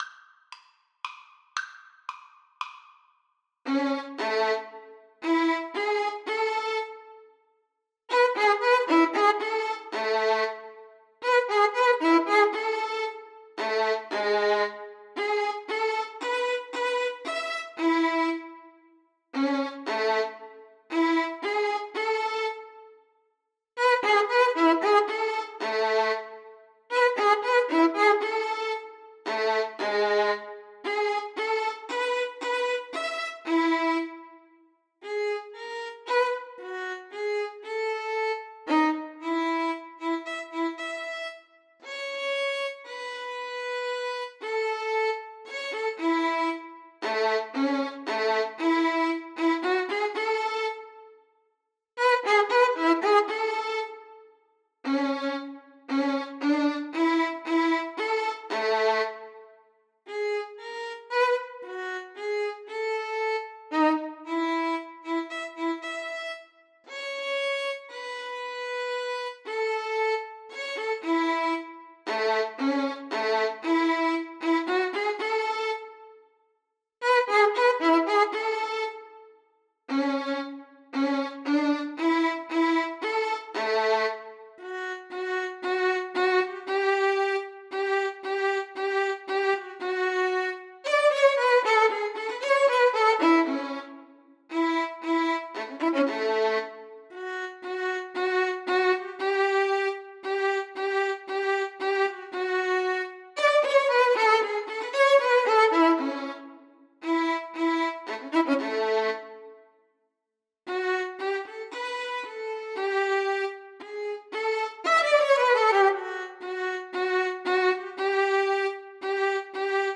Violin 1Violin 2
3/4 (View more 3/4 Music)
Arrangement for Violin Duet
A major (Sounding Pitch) (View more A major Music for Violin Duet )
Classical (View more Classical Violin Duet Music)